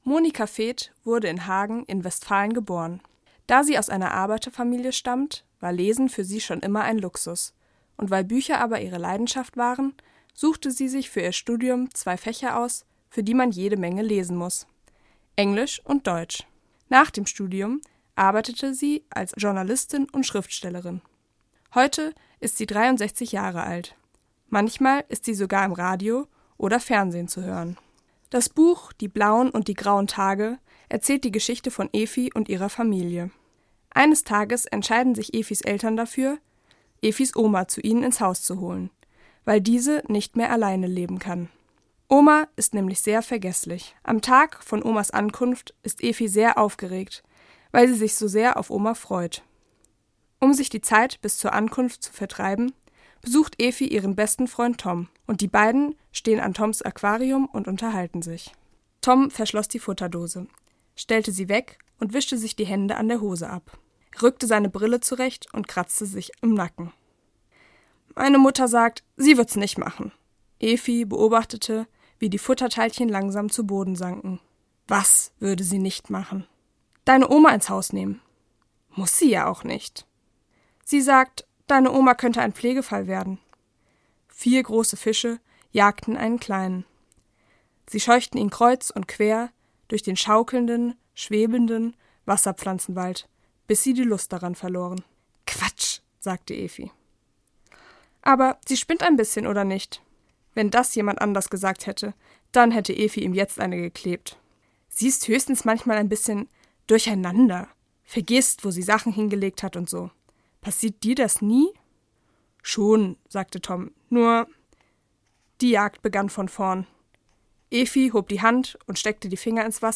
Buchvorstellung: Die blauen und die grauen Tage von Monika Feth